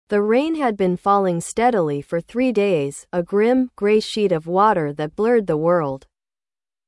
Foco: Sentir o ritmo melancólico e as consoantes longas (rain, grim, gray).